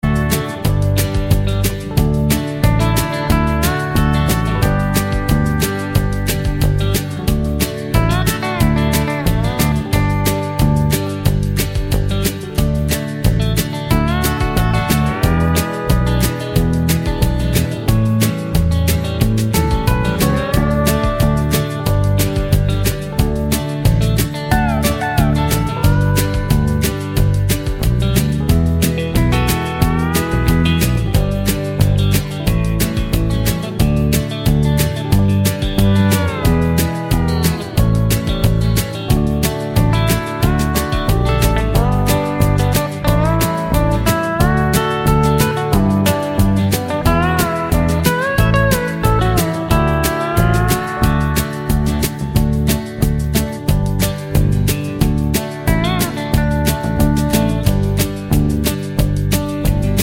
Key of F# Country (Male) 3:33 Buy £1.50